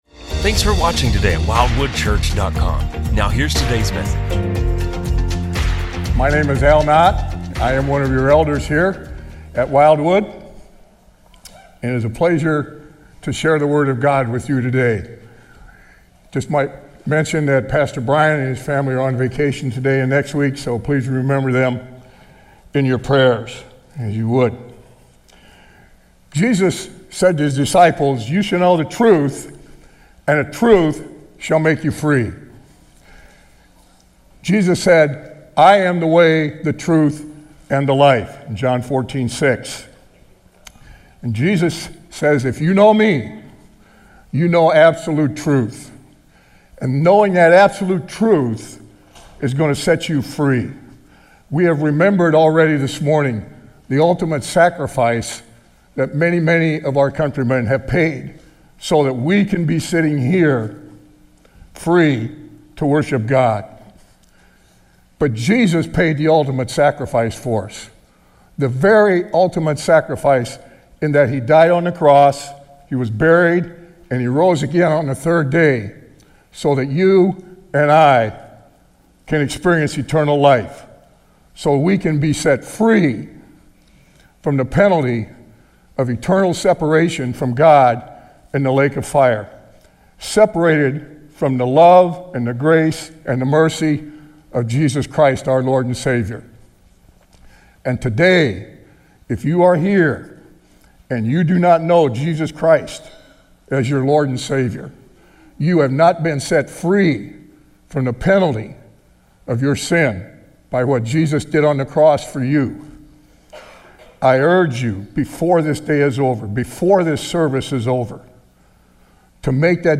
Wildwood Church Sermons